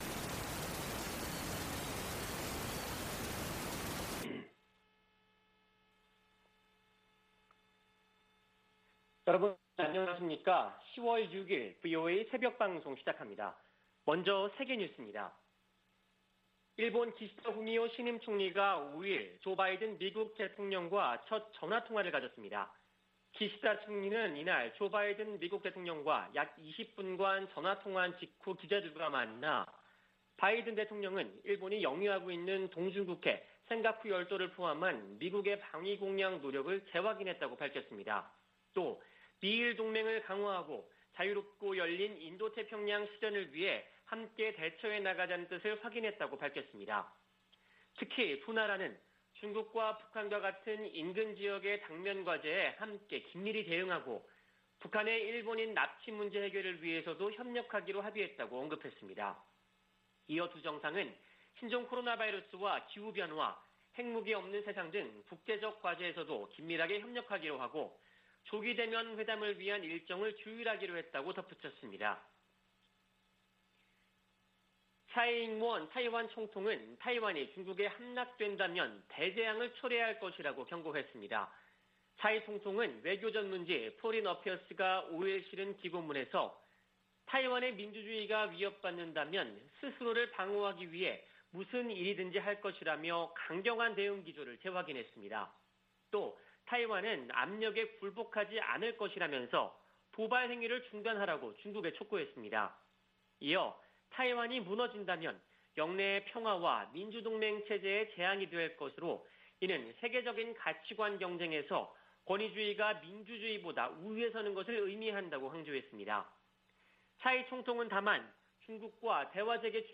VOA 한국어 '출발 뉴스 쇼', 2021년 10월 6일 방송입니다. 유엔 안보리 전문가패널이 북한의 제재 위반 사례를 담은 보고서를 공개했습니다. 미 국무부는 북한이 안보리 긴급회의 소집을 비난한 데 대해 유엔 대북제재의 완전한 이행 필요성을 강조했습니다. 문재인 한국 대통령은 남북한 체재경쟁이나 국력 비교는 의미 없어진 지 오래라며 협력 의지를 밝혔습니다.